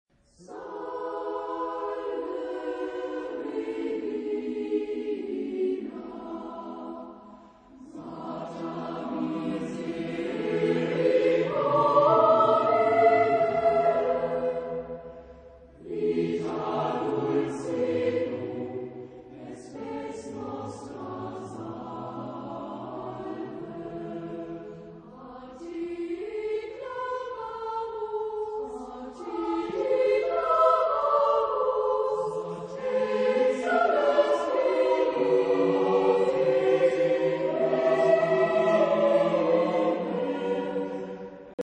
Genre-Style-Form: Romantic ; Sacred ; Motet
Type of Choir: SATB  (4 mixed voices )
Tonality: E major
sung by Knabenchor Bremen
: 7. Deutscher Chorwettbewerb 2006 Kiel